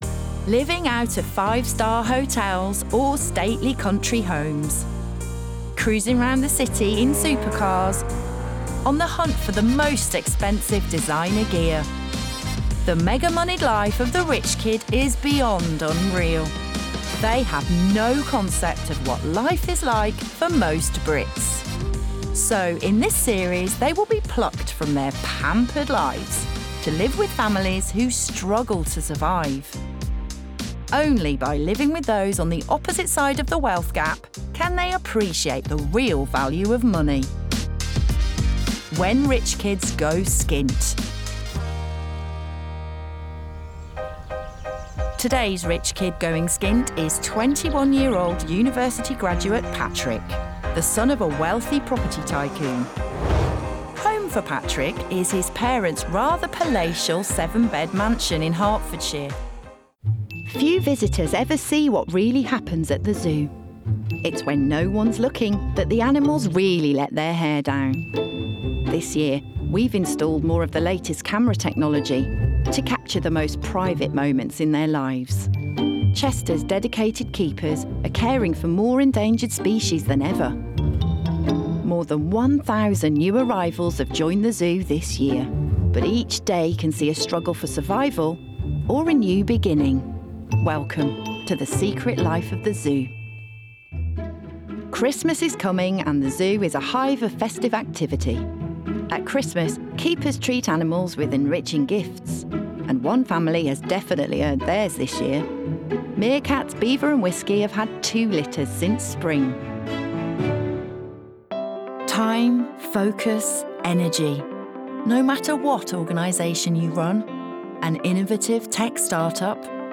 • Native Accent: Black Country
• Home Studio